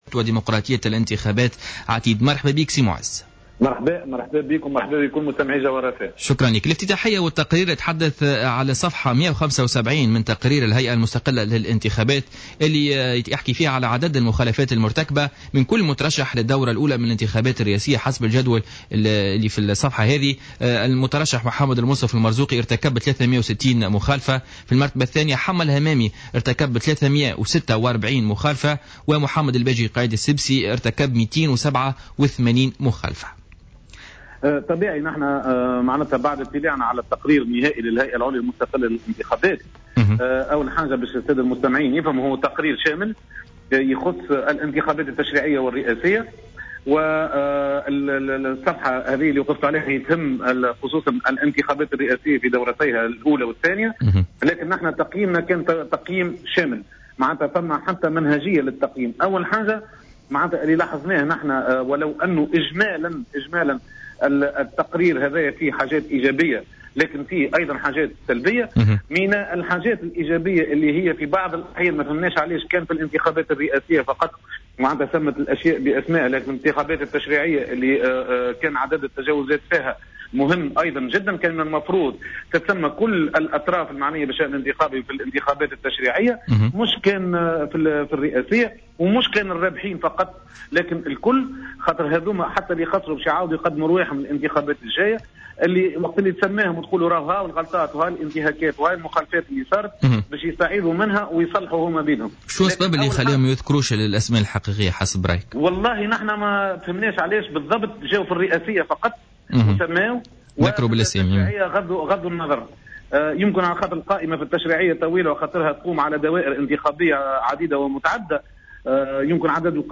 في مداخلة له في برنامج بوليتيكا